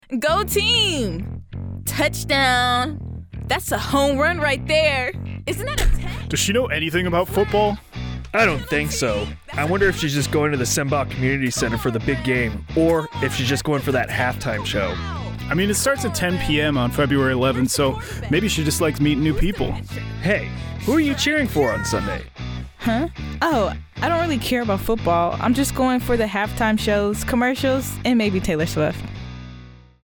AFN Commercial Spot - Big Game Viewing at the Sembach CAC